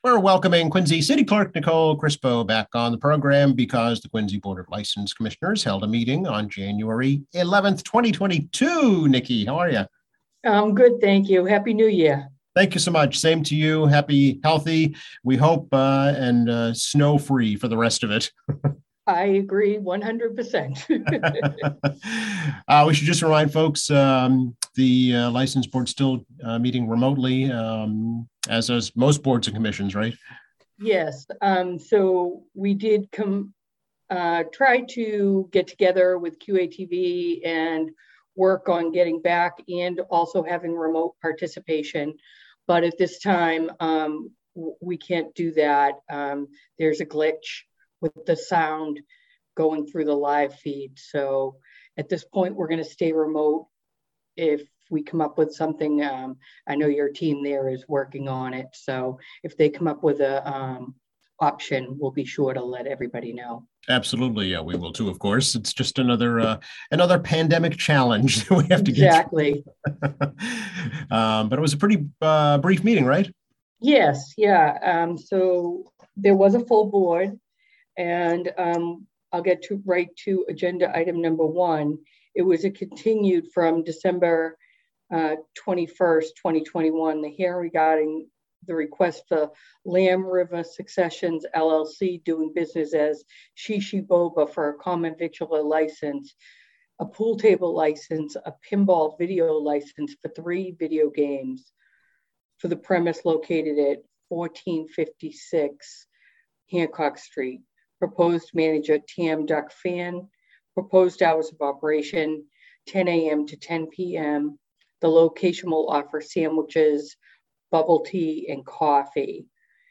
Quincy City Clerk Nicole Crispo explains the actions taken at the January 11th Board of License Commissioners meeting.